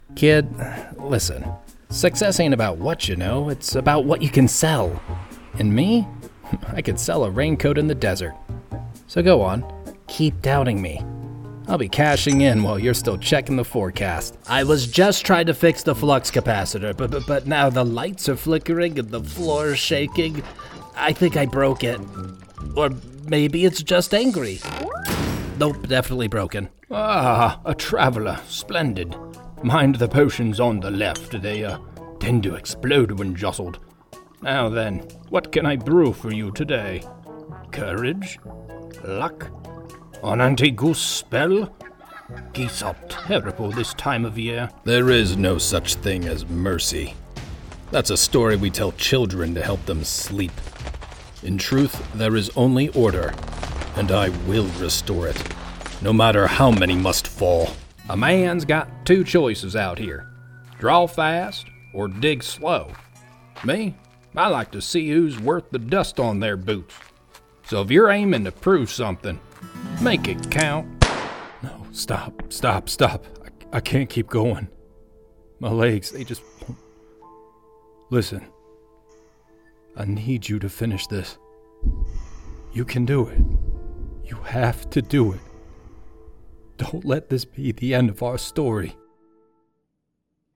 Male
My voice is grounded, clear, and medium to medium-low in tone, ideal for authentic, conversational reads.
Character / Cartoon
Animated Voices & Roles